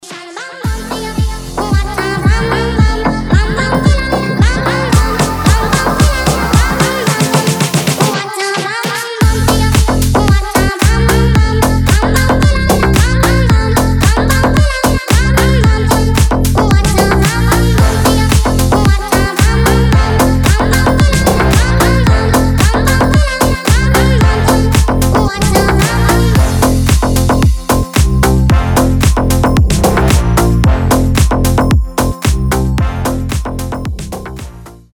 • Качество: 320, Stereo
позитивные
забавные
веселые
slap house